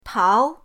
tao2.mp3